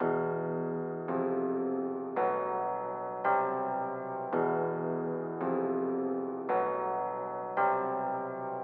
111BPM 808
标签： 111 bpm Trap Loops Drum Loops 1.46 MB wav Key : Unknown
声道立体声